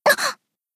BA_V_Wakamo_Swimsuit_Battle_Damage_2.ogg